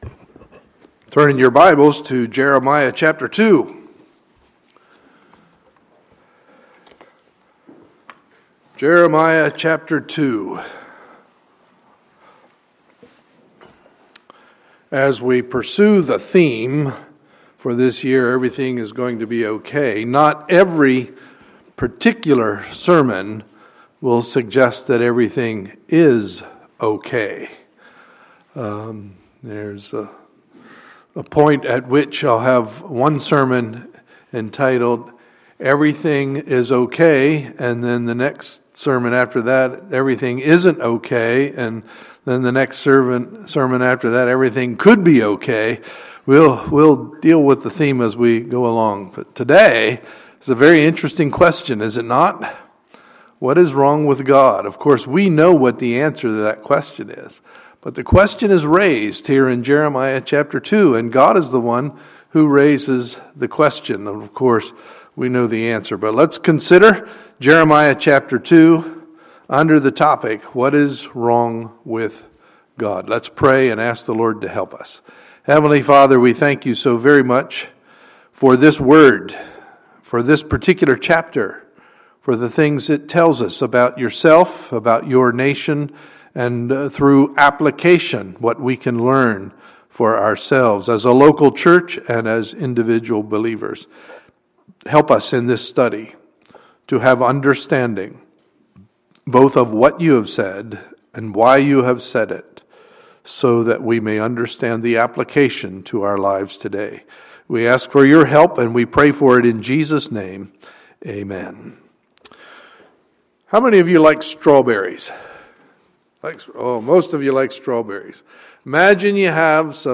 Sunday, January 18, 2015 – Sunday Morning Service